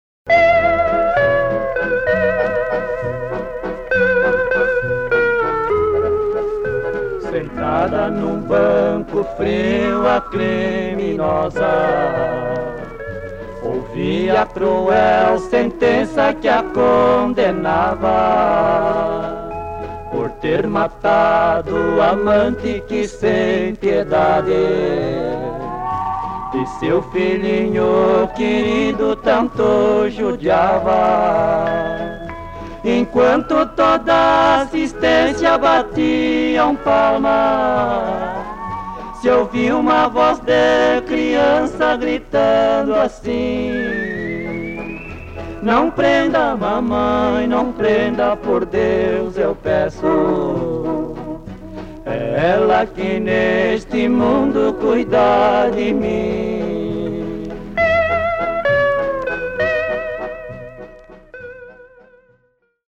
VOZ DE CRIANÇA Drama teatral em 05 atos